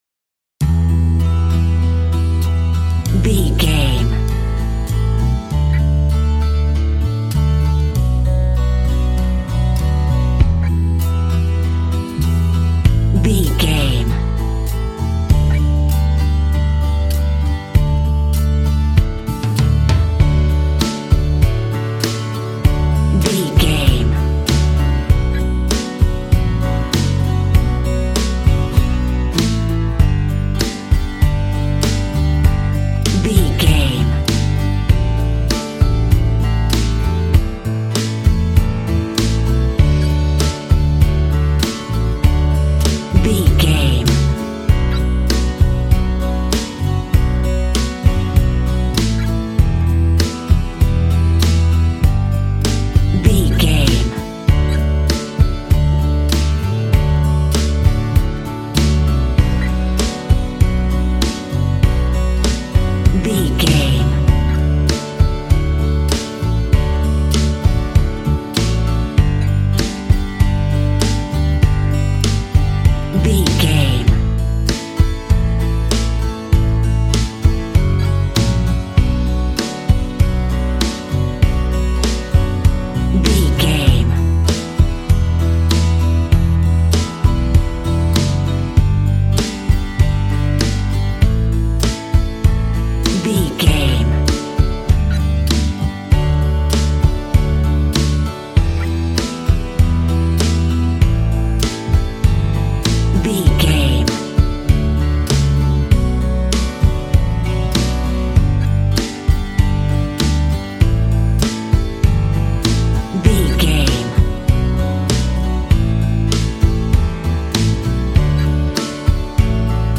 A romantic piece of country music for lovers.
Ionian/Major
D
Fast
bouncy
double bass
drums
acoustic guitar